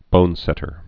(bōnsĕtər)